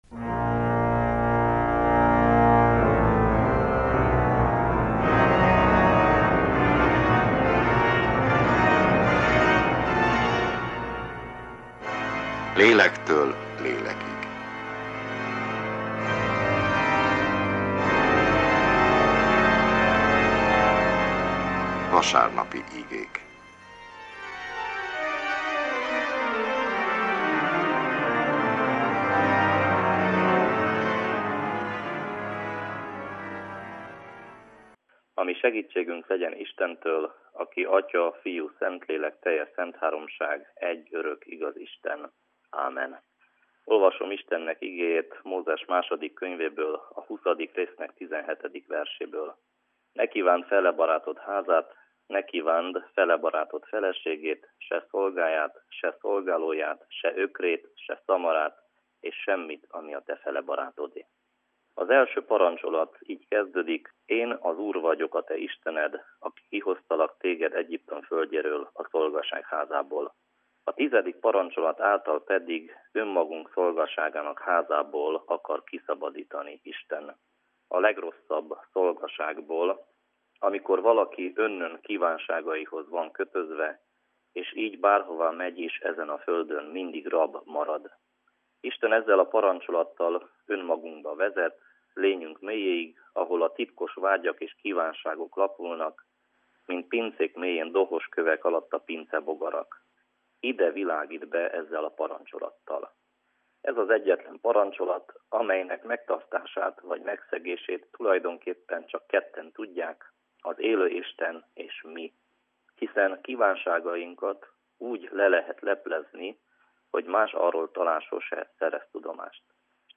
Református igehirdetés, augusztus 14.
igehirdetés